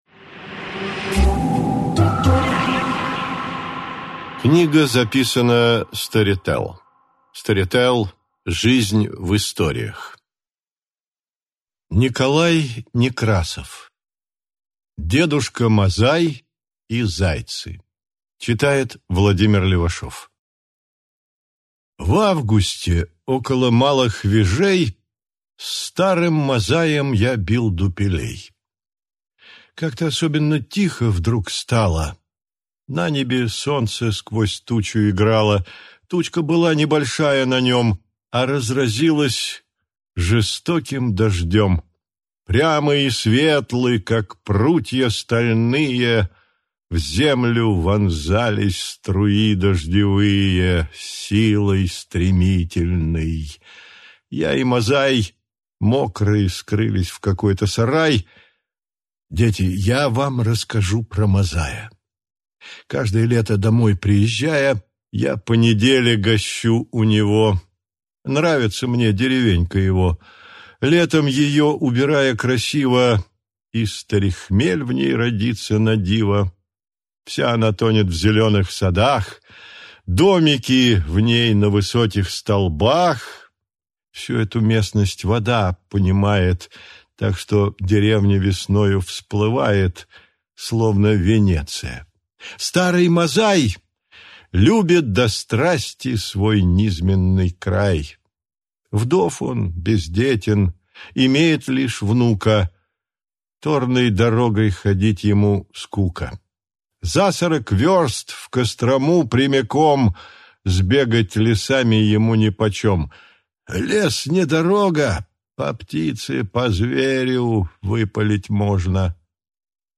Аудиокнига Дедушка Мазай и зайцы | Библиотека аудиокниг